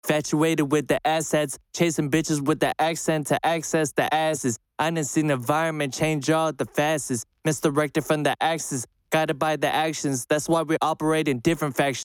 High end resonance/whistling within recorded vocals.
I thought it was my RODE NT1A microphone that was causing it but I tried recording with my MXL 990 and it still had the same issue.
View attachment Whistling vocals.mp3
If anything, it's dark sounding and lacking 'air' (4khz and above)
I hear some sibilance, but no whistling.
It sounds like 'z's.
I have always had bad experience with NT1a for direct male vocals like yours.
It has a sizzly tone to it.